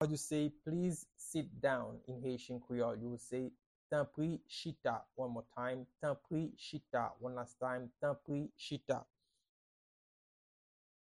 Pronunciation and Transcript:
Please-sit-down-in-Haitian-Creole-Tanpri-chita-pronunciation-by-a-Haitian-Creole-teacher.mp3